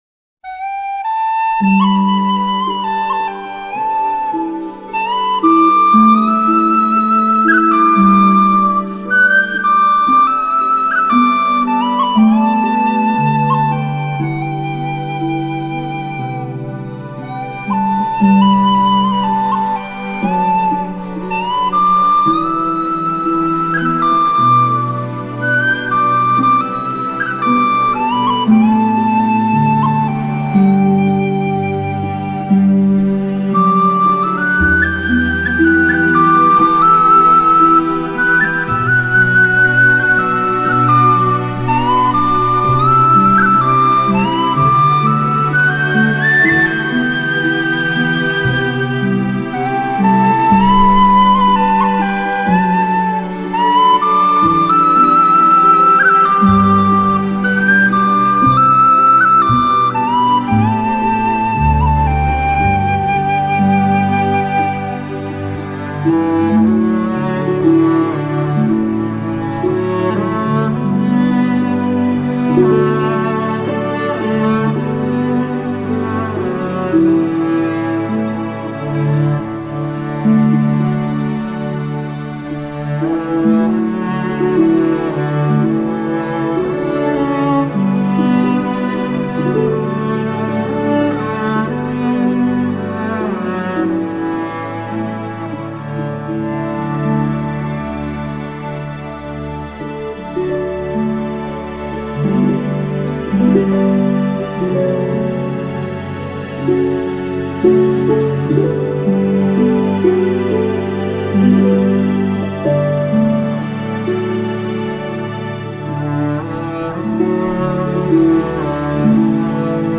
0121-爱尔兰哨笛.mp3